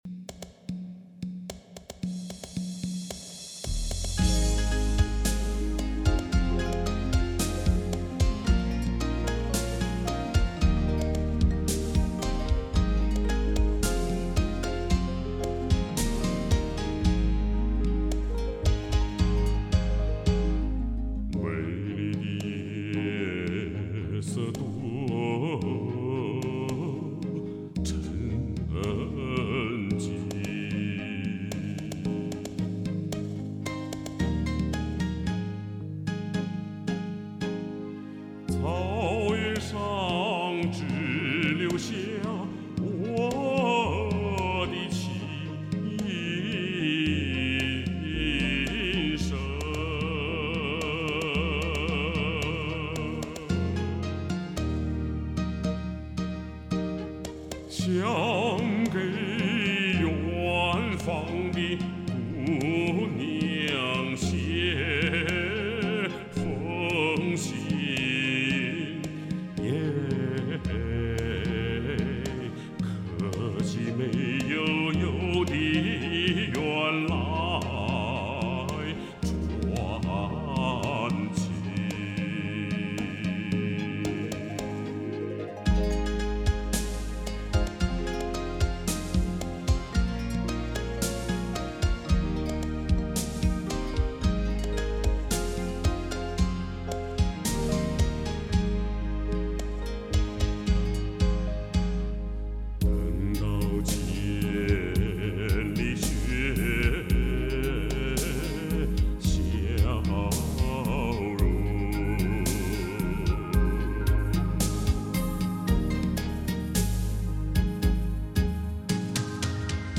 ），河北唐山人，男低音歌唱家，总政歌舞团国家一级演员。
形成了雄浑厚重、抒情豪放、真切委婉的演唱风格。